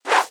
Whoosh 1.ogg